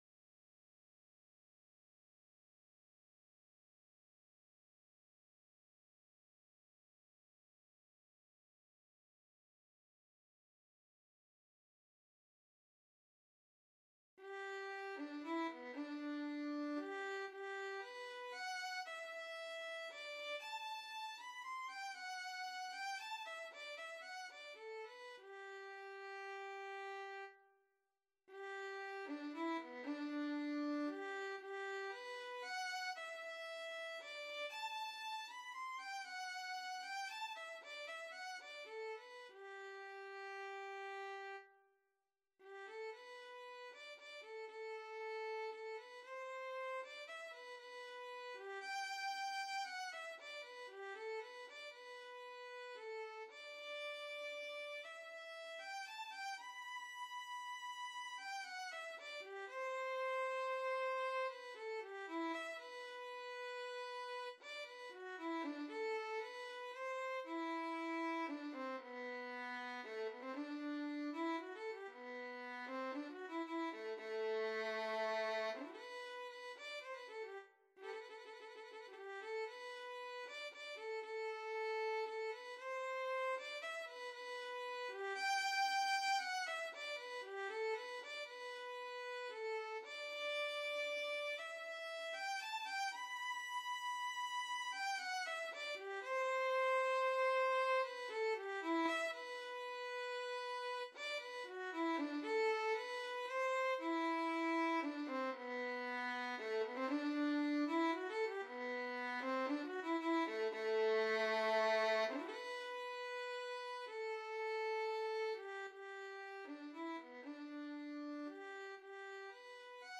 3/4 (View more 3/4 Music)
Allegretto =116 Allegretto =120
Classical (View more Classical Violin-Guitar Duet Music)